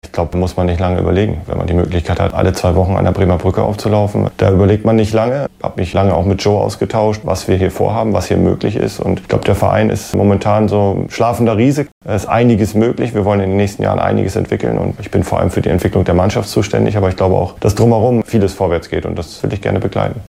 Timo Schultz bei seiner Vorstellung am Dienstag zu den Zielen mit dem VfL.